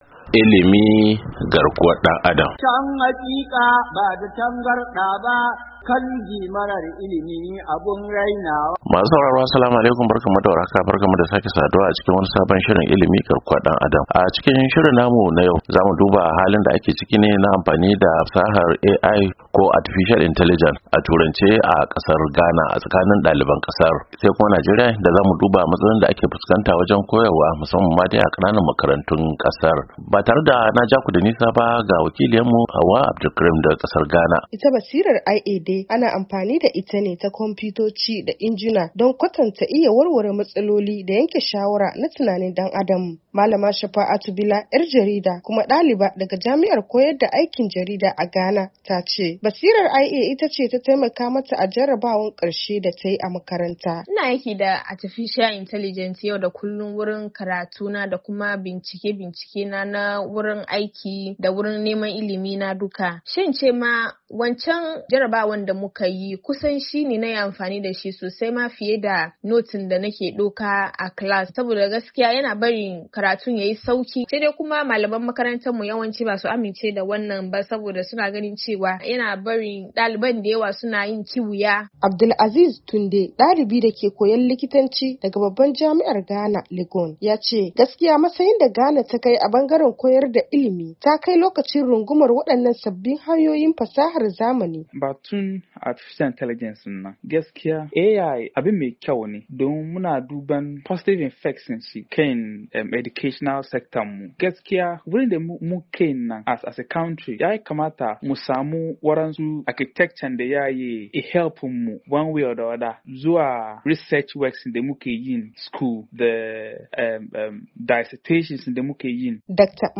Shirin ilimantarwa na wannan makon ya leka Ghana ta hanyar tattaunawa da malamai da dalibai kan fa'ida da rashin amfani da AI a jami'o'in Ghana.